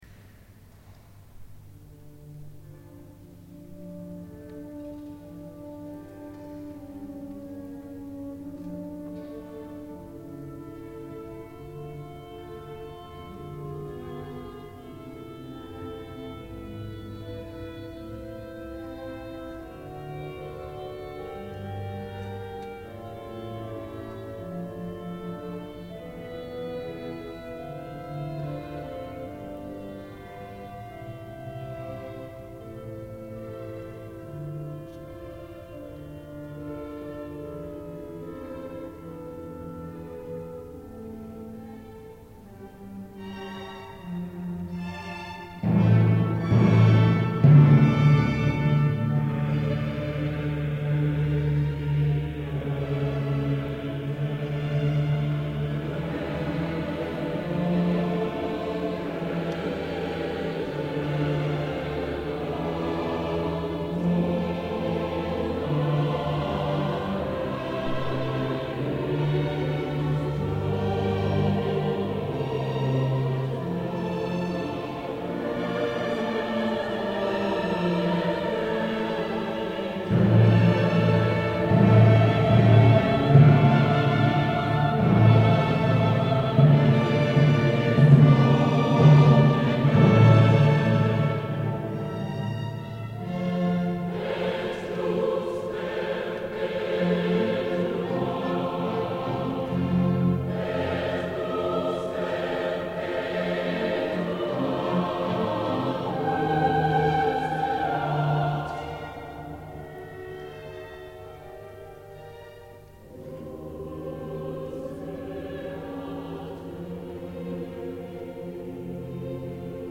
Concierto en la Catedral de Cadiz
Coro 1 - Coro 2 Coro Final con aplausos El concierto completo de 50 min.